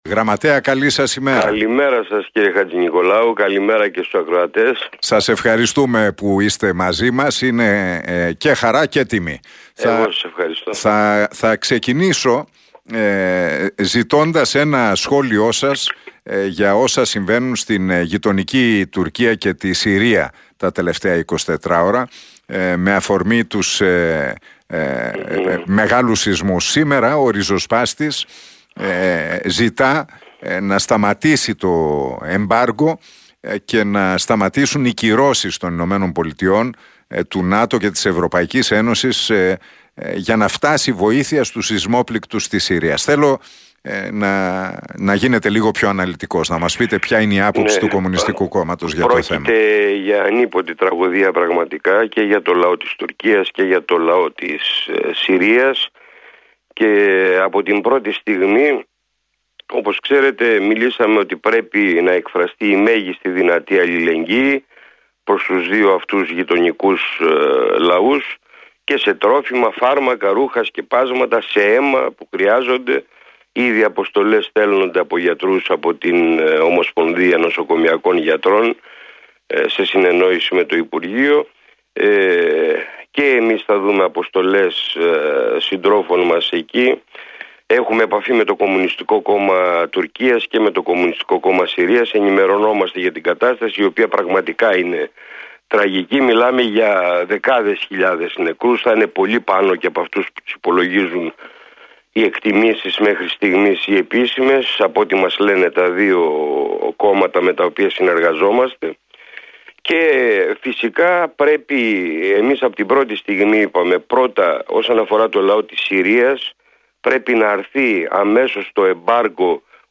Ο ΓΓ της ΚΕ του ΚΚΕ, Δημήτρης Κουτσούμπας, παραχώρησε συνέντευξη εφ' όλης της ύλης στον Realfm 97,8 και στον Νίκο Χατζηνικολάου.